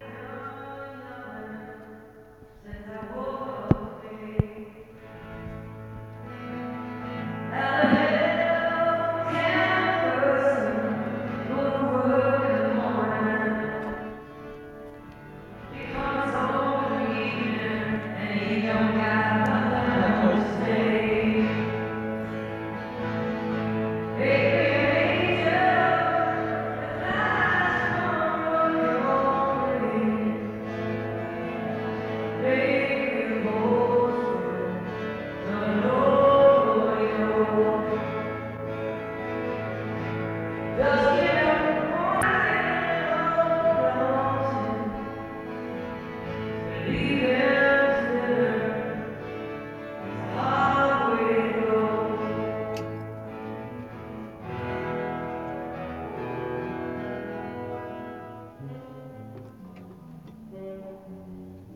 acoustic duo
soundcheck